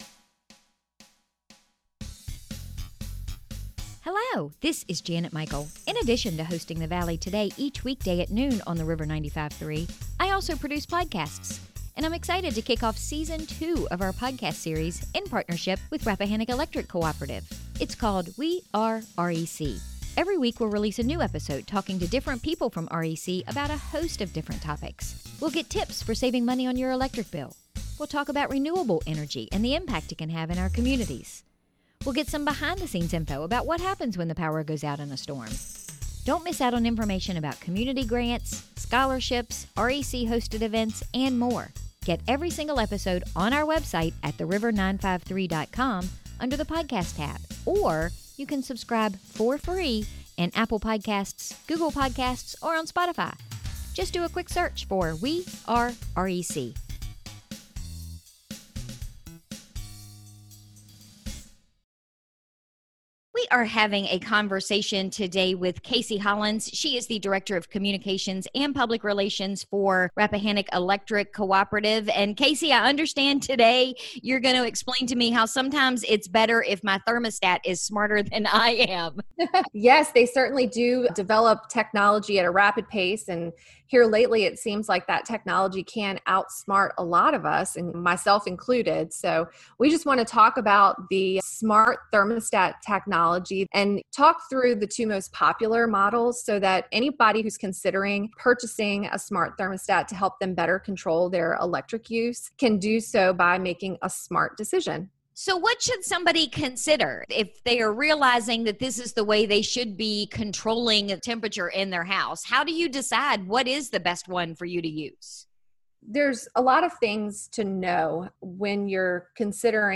We recorded today’s episode via Zoom